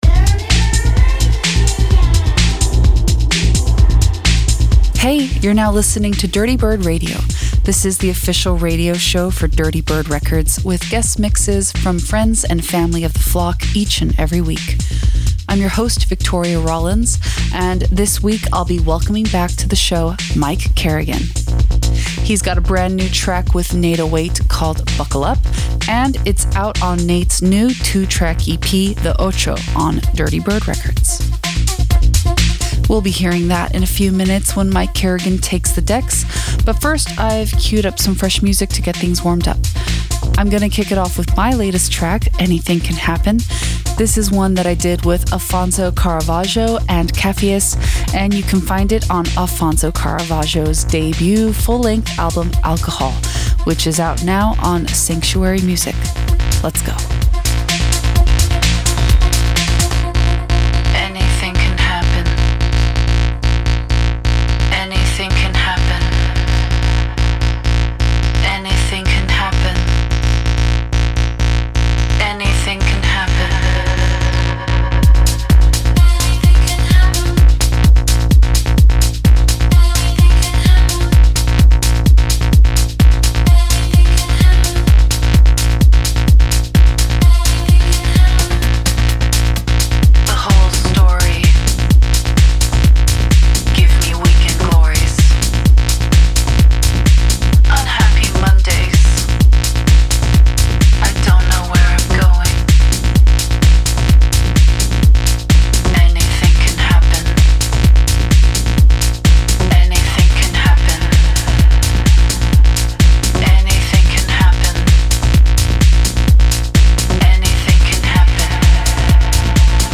big bass conductor
guest set